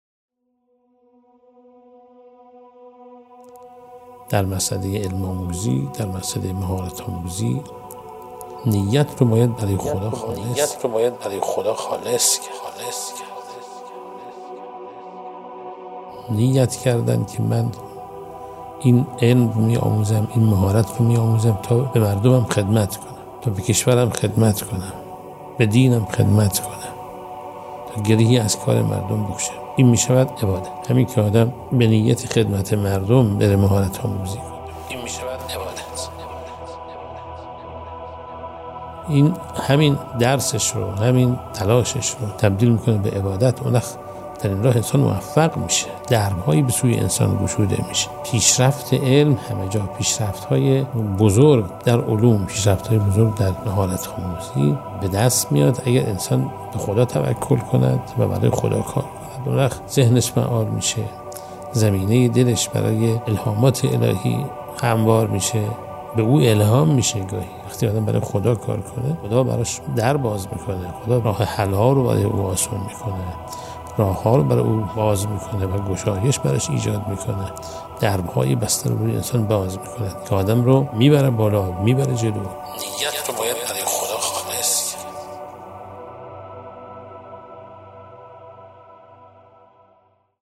پادکست بیانات آیت الله محسن اراکی درباره اخلاص که این اثر توسط بنده در مرکز رسانه ای دفتر معظم له تولید شده و در سایت رسمی و شبکه های اجتماعی آیت الله اراکی منتشر شده است.